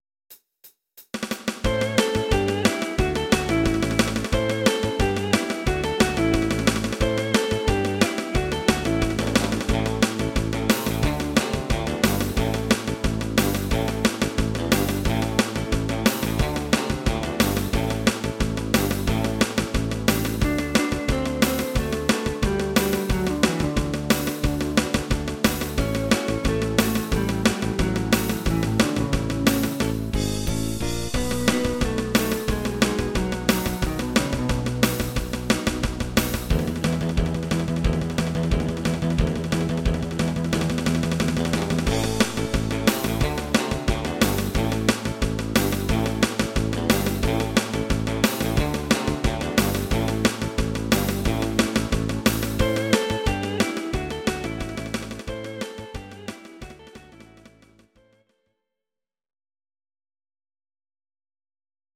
These are MP3 versions of our MIDI file catalogue.
Please note: no vocals and no karaoke included.
inst. Gitarre